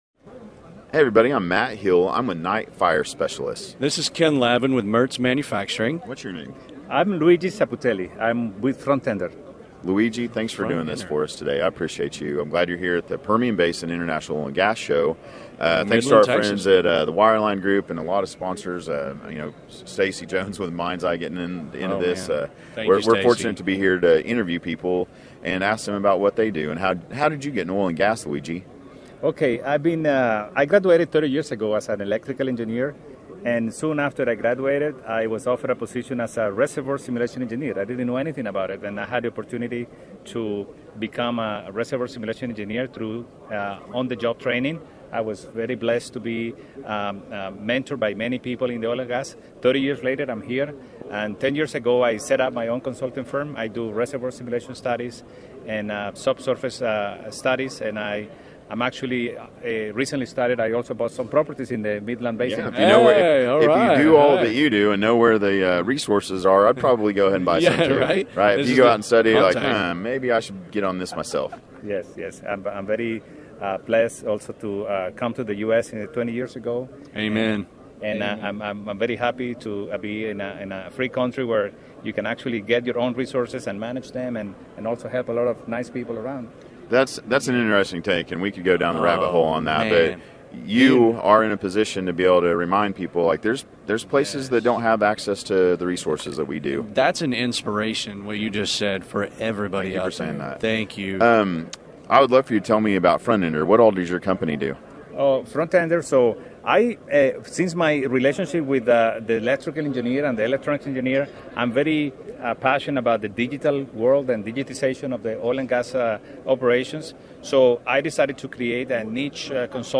Full Length Interviews
The PBIOS is a three-day gathering of producers, service companies, investors and innovators, where ideas are exchanged, friendships are made and deals are born.